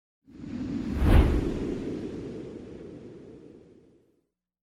Звуки воздуха
Звук перехода воздуха